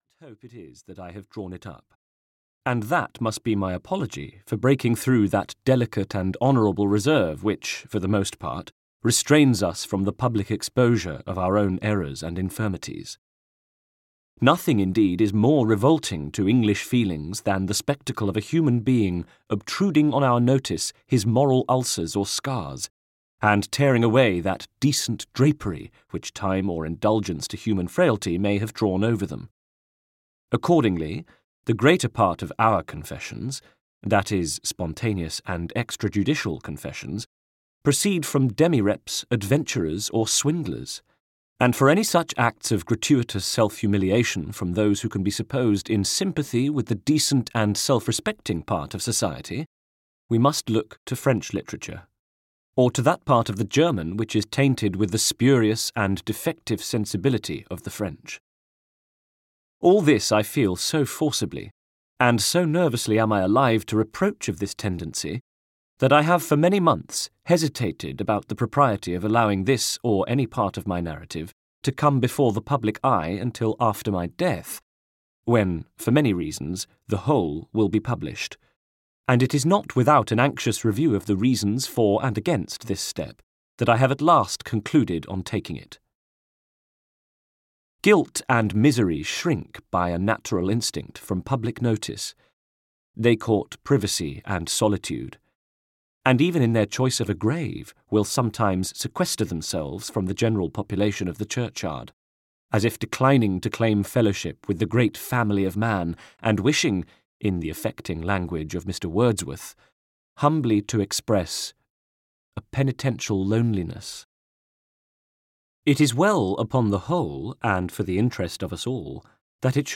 Confessions of an English Opium-Eater (EN) audiokniha
Ukázka z knihy